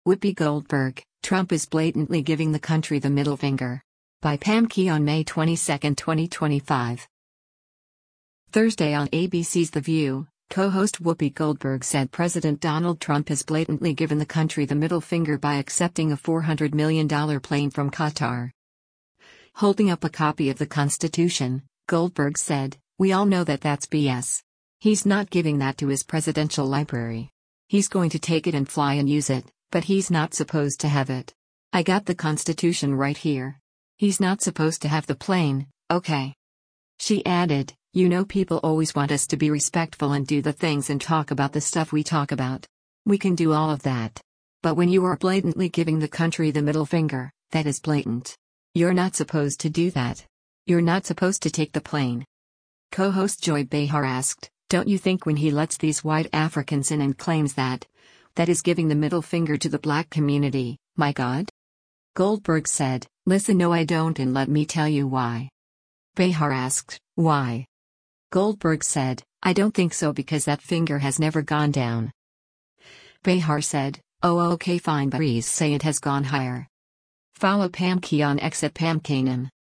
Thursday on ABC’s “The View,” co-host Whoopi Goldberg said President Donald Trump has “blatantly” given the country the middle finger by accepting a $400 million plane from Qatar.
Co-host Joy Behar asked, “Don’t you think when he lets these white Africans in and claims that, that is giving the middle finger to the black community, my God?”